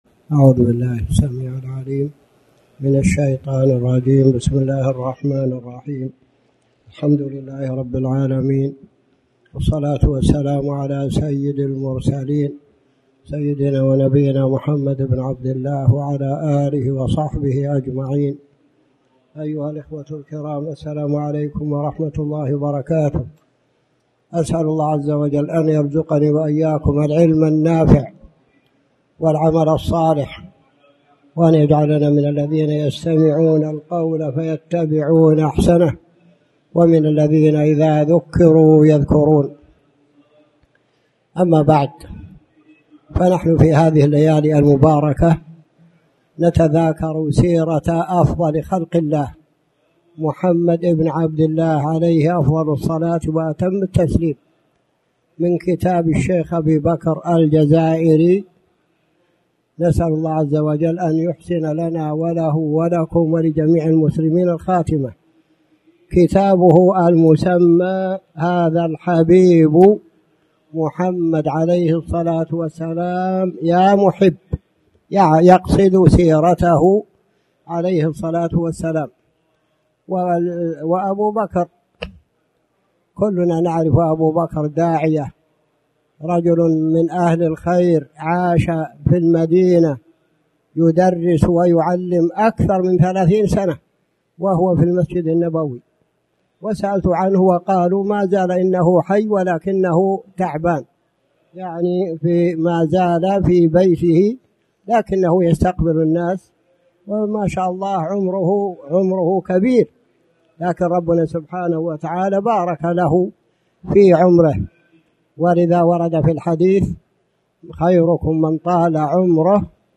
تاريخ النشر ١٩ محرم ١٤٣٩ هـ المكان: المسجد الحرام الشيخ